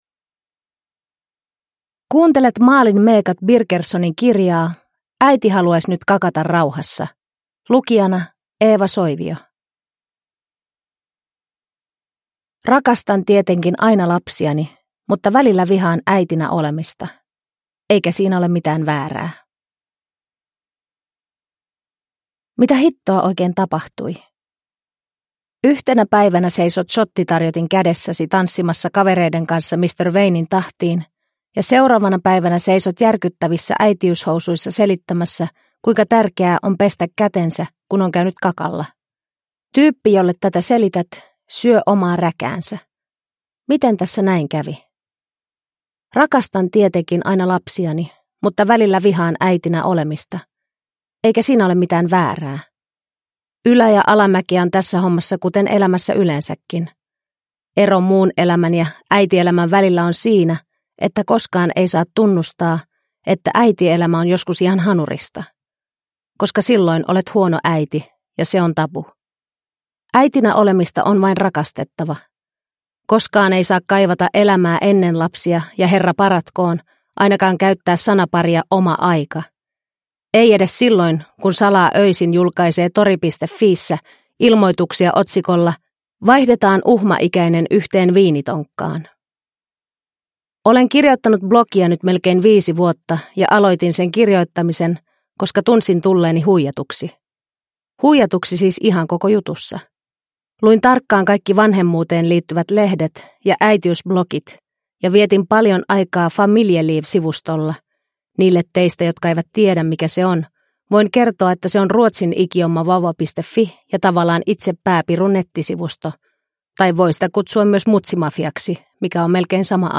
Äiti haluais nyt kakata rauhassa! – Ljudbok